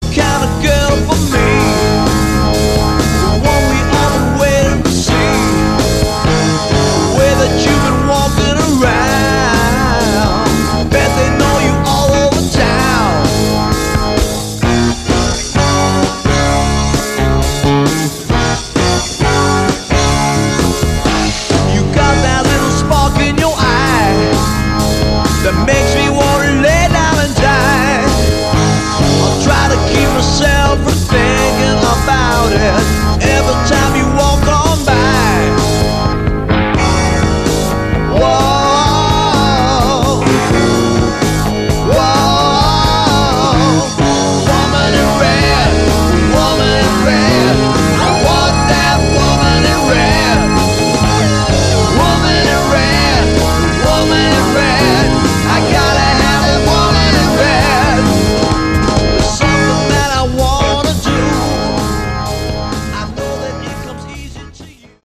Category: Melodic Rock
lead vocals, backing vocals
guitar
keyboards
bass
drums